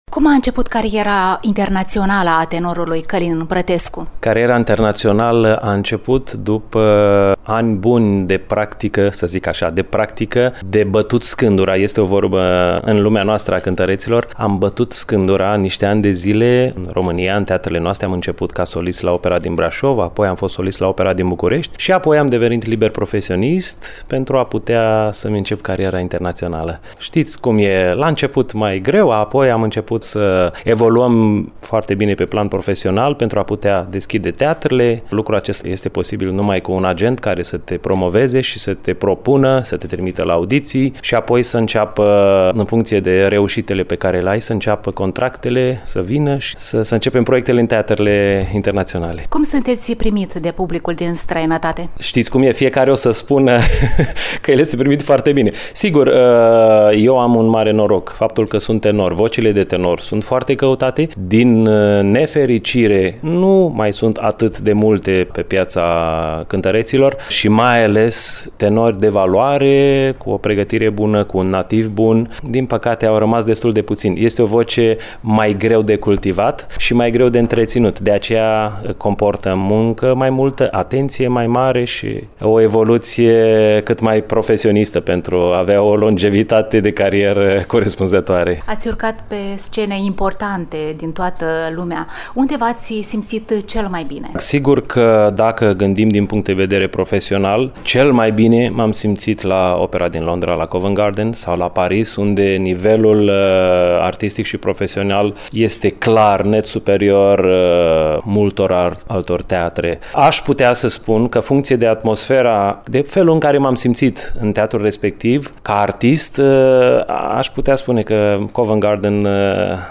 dialogul